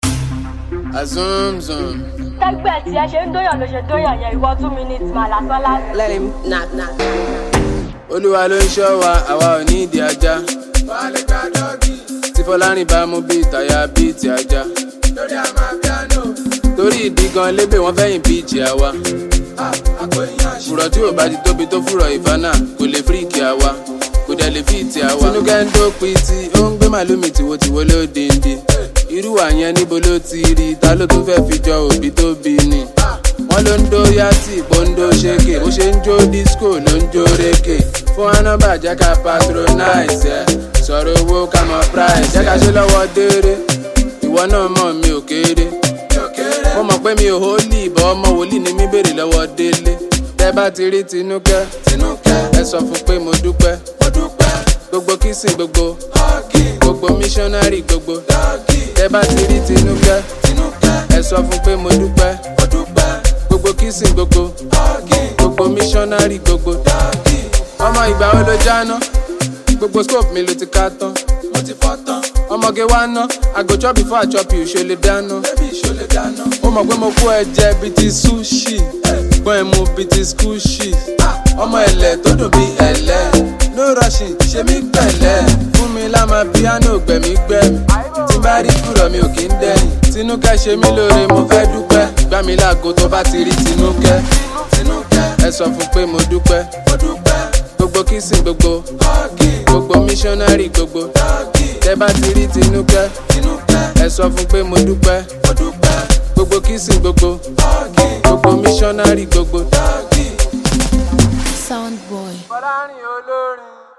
Afrobeat